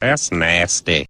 PLAY that’s nasty meme
Play, download and share nasty original sound button!!!!